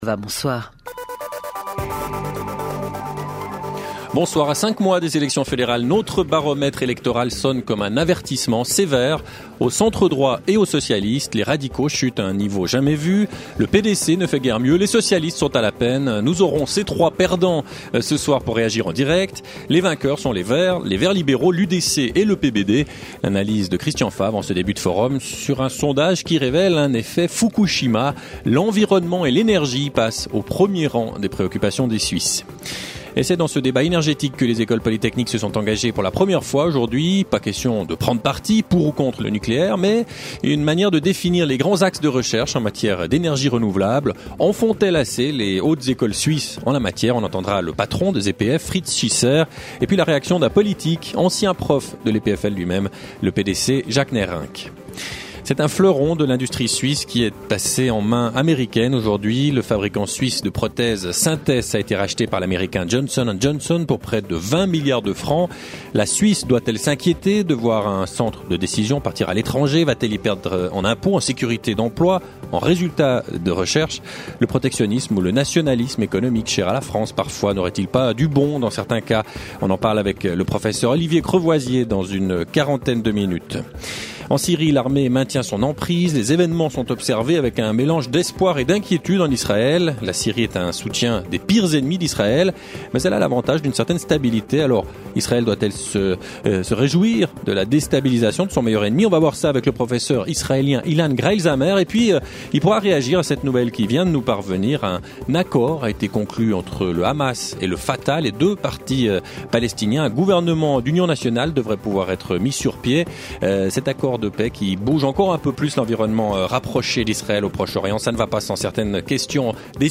7 jours sur 7, Forum questionne en direct les acteurs de l’actualité, ouvre le débat sur les controverses qui animent la vie politique, culturelle et économique.